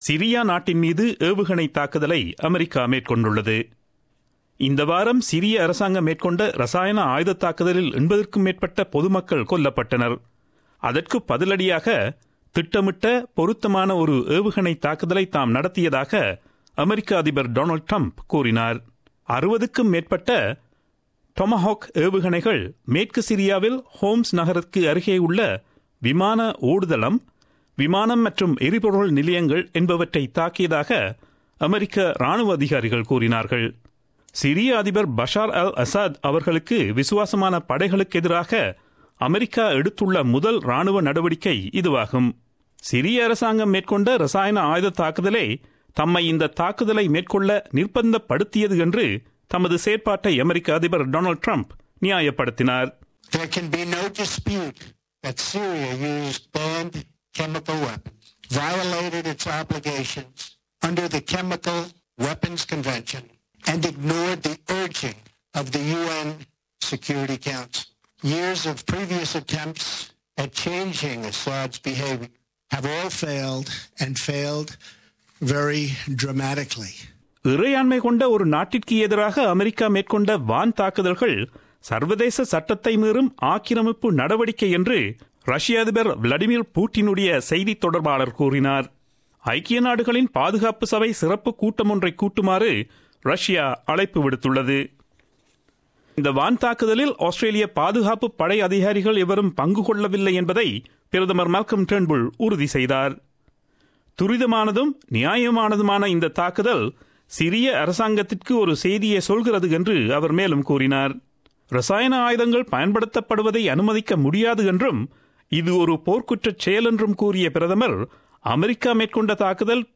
Australian news bulletin aired on Friday 07 Apr 2017 at 8pm.